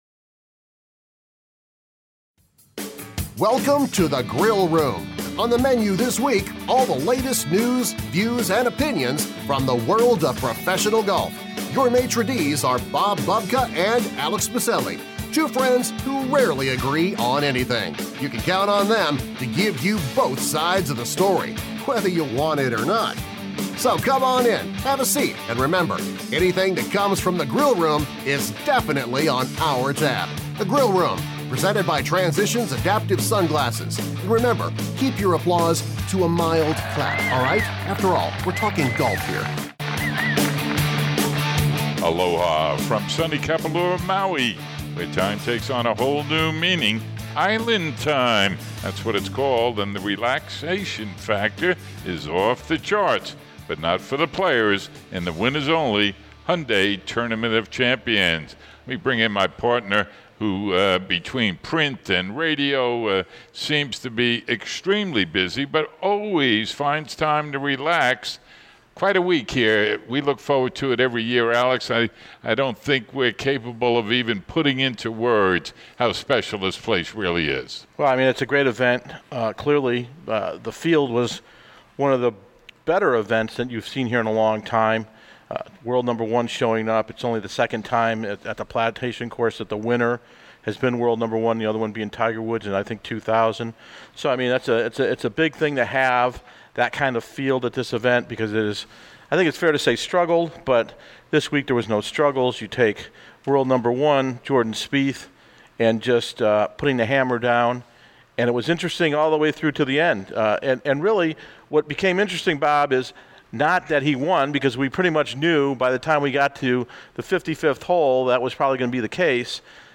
The Grill Room kicks of a new talk show format this week with the complete domination of the domination of the Hyundai Tournament of Champions by Jordan Spieth.
Feature interview